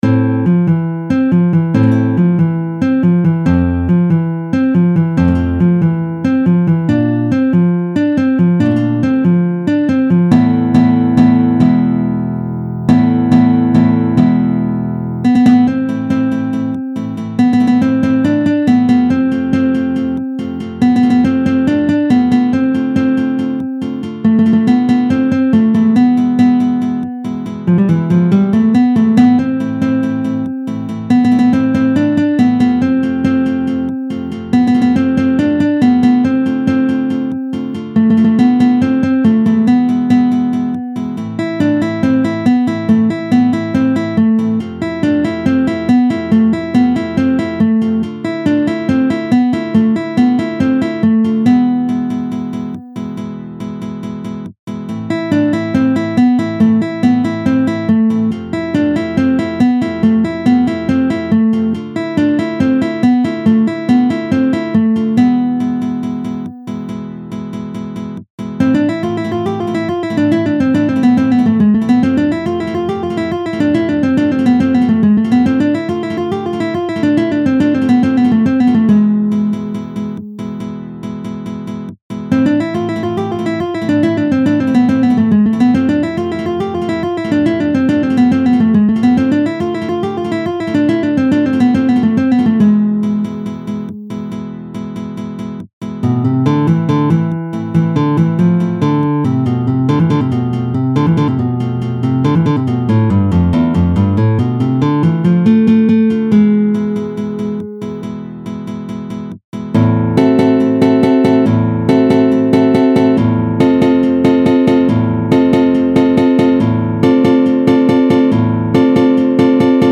قبل از دانلود فایل صوتی (اجرا شده با نرم‌افزار) را گوش کنید.